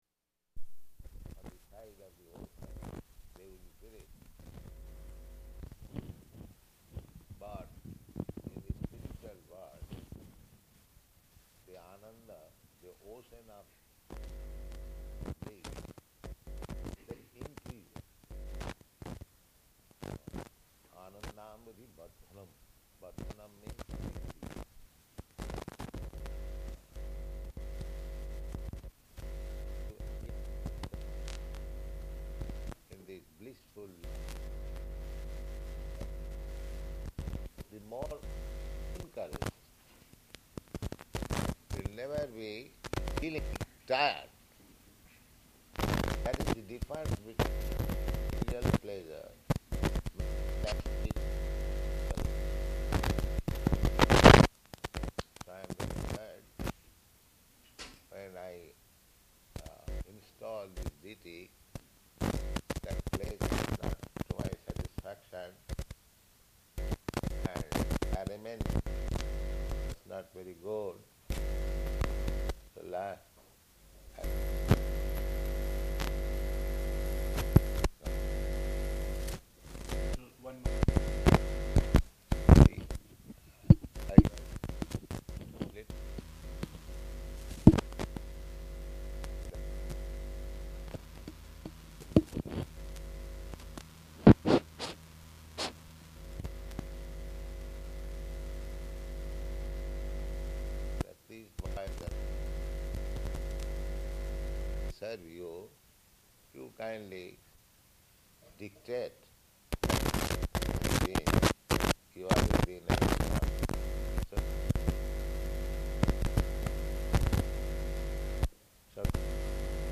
Room Conversation
Location: Paris
[Poor audio]